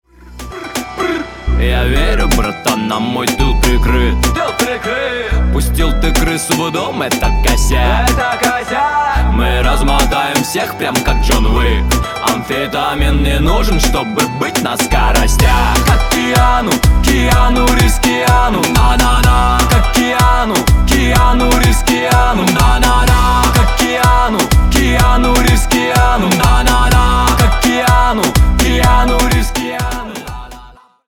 Рок Металл
весёлые